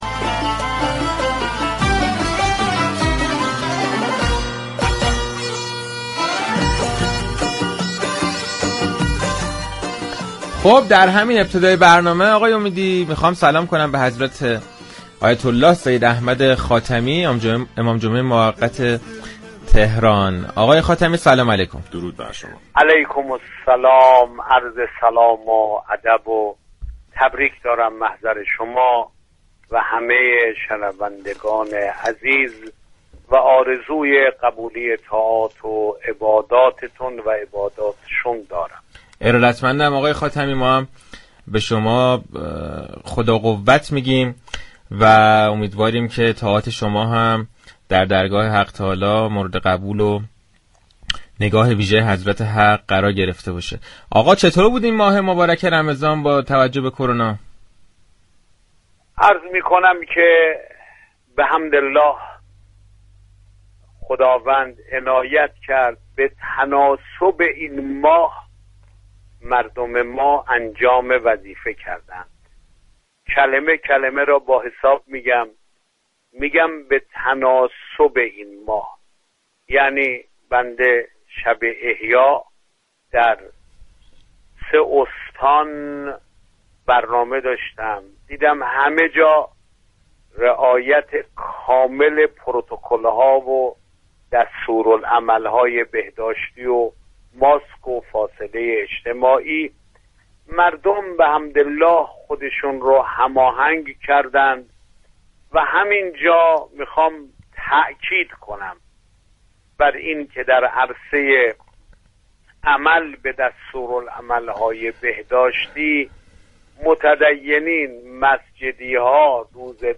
به گزارش پایگاه اطلاع رسانی رادیو تهران؛ حجت الاسلام سید احمد خاتمی امام جمعه موقت تهران در گفتگو با برنامه جشن سپاس، ویژه برنامه عید فطر رادیو تهران درباره ماه رمضانی كه گذشت گفت: مردم دیندار خود را با شرایط هماهنگ كردند و با دستورالعمل های بهداشتی در مراسم شبهای قدر حاضر شدند و تا پایان شیوع این بیماری در خط مقدم خواهند ماند.